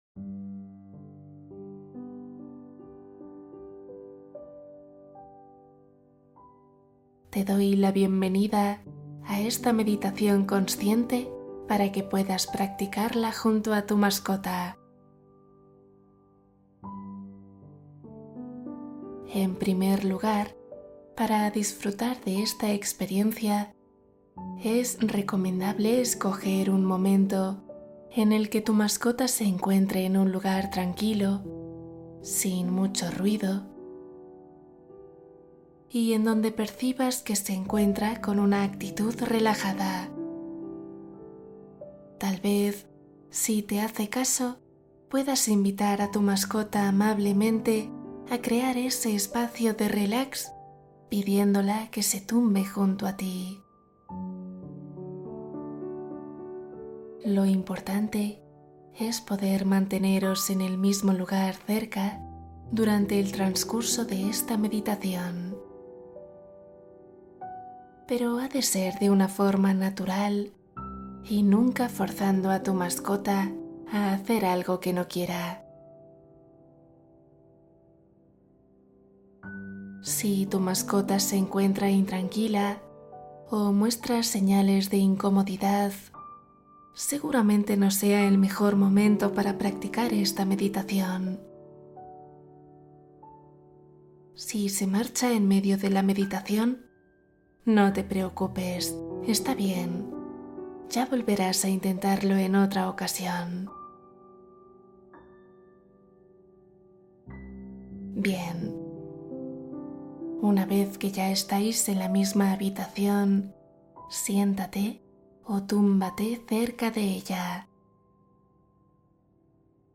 Meditación matutina 12 minutos para empezar bien el día